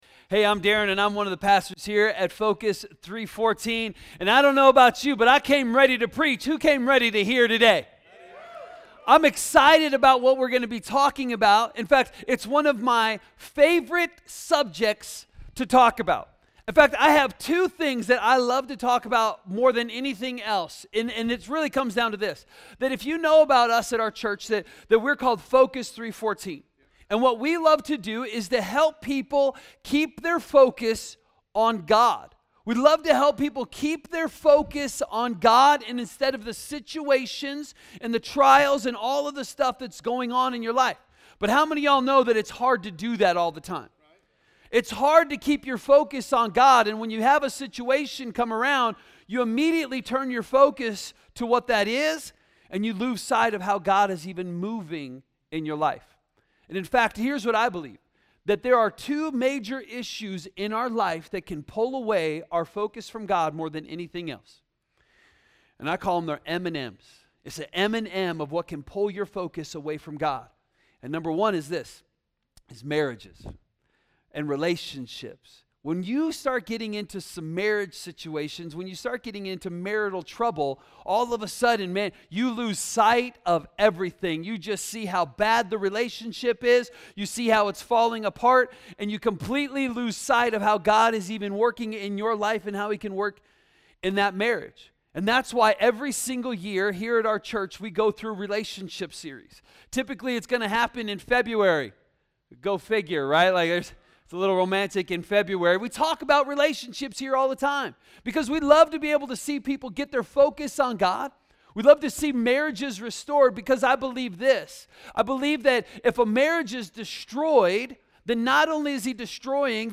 A message from the series "Making Change."